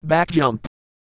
Worms speechbanks
jump2.wav